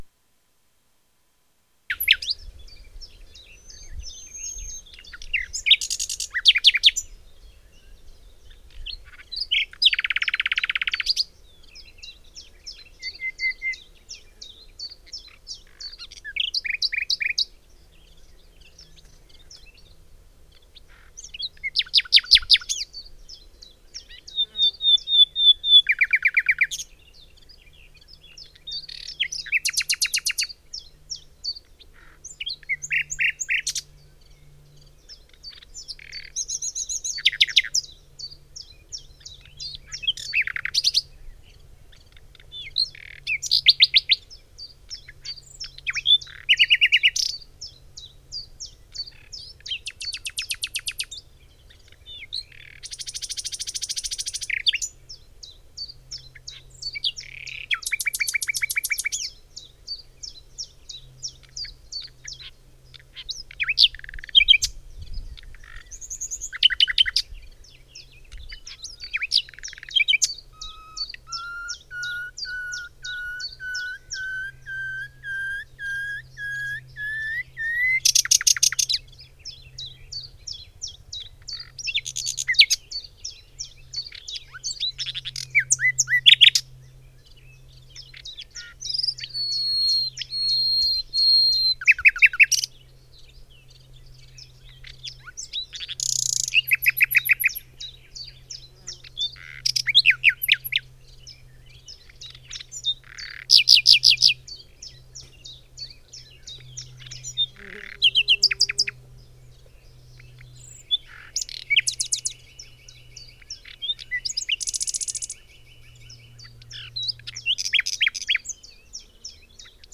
Nightingale song 3
bird birdsong bulbul csalogany etelansatakieli field-recording forest fulemule sound effect free sound royalty free Nature